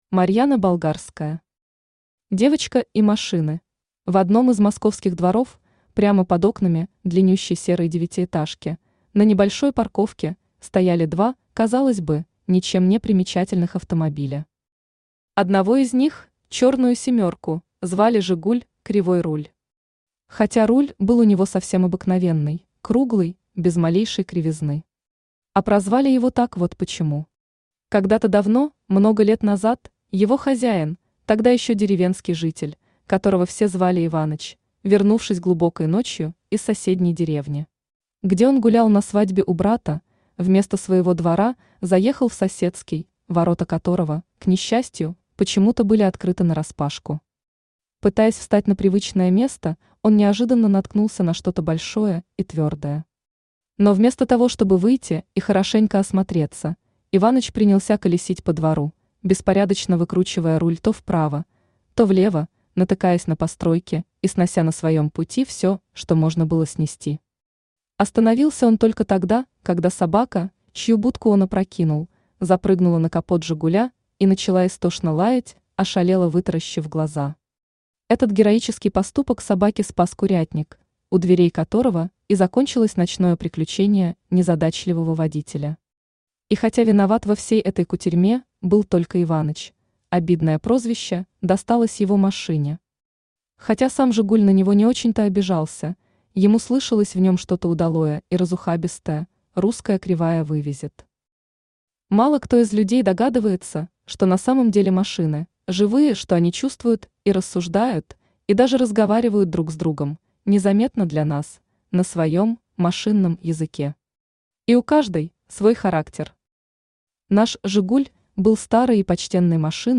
Аудиокнига Девочка и машины | Библиотека аудиокниг
Aудиокнига Девочка и машины Автор Марьяна Болгарская Читает аудиокнигу Авточтец ЛитРес.